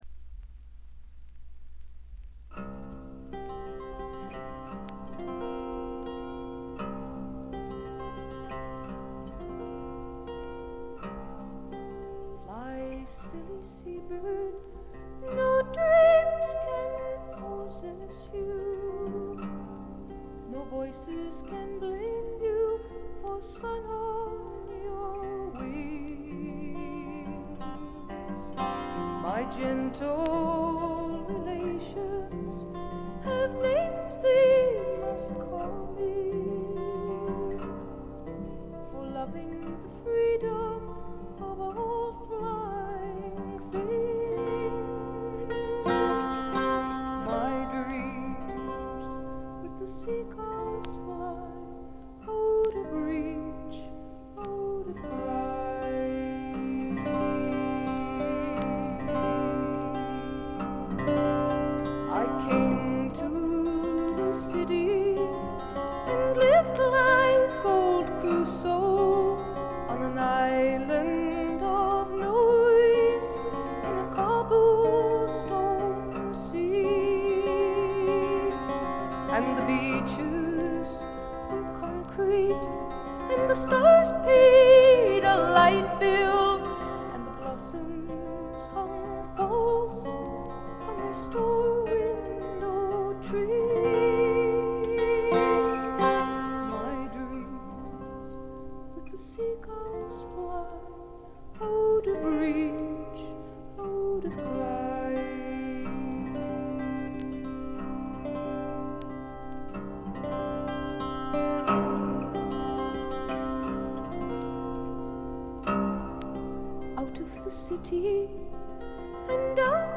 全体にバックグラウンドノイズ。
至上の美しさをたたえたサイケ・フォーク、アシッド・フォークの超絶名盤という見方もできます。
試聴曲は現品からの取り込み音源です。
guitar, piano, vocals